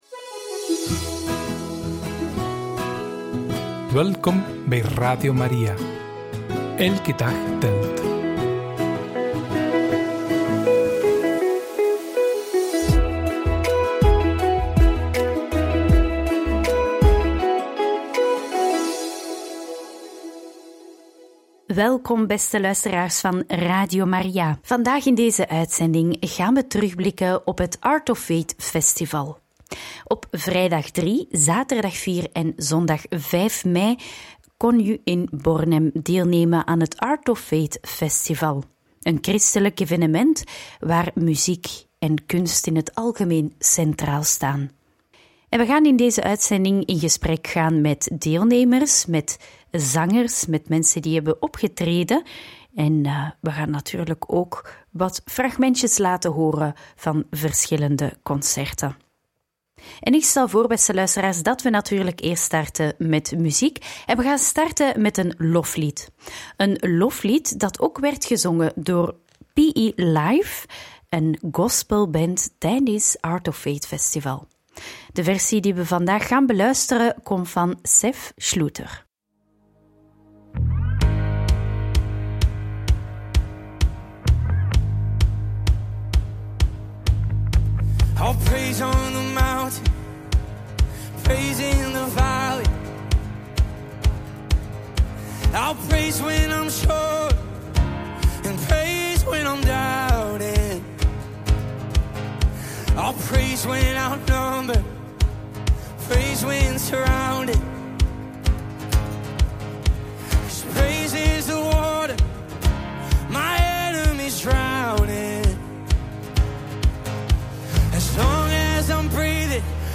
Gesprekken op het Art of Faith Festival